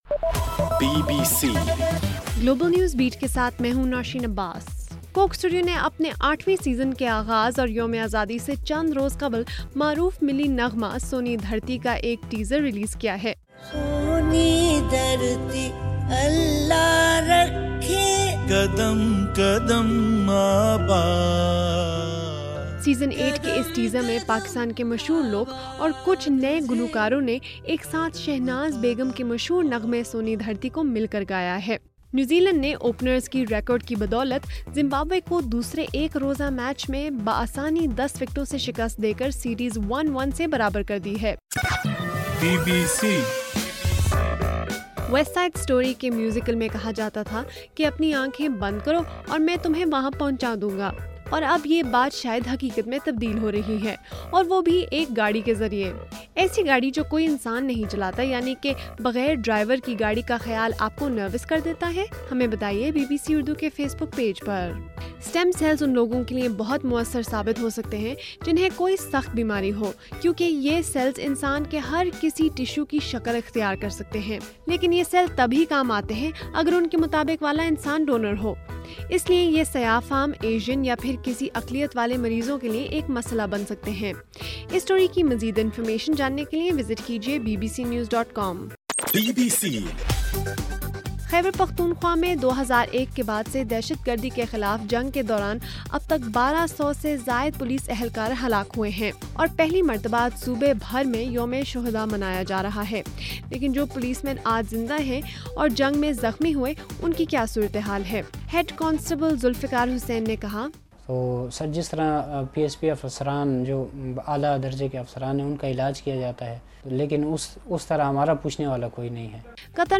اگست 4: رات 10 بجے کا گلوبل نیوز بیٹ بُلیٹن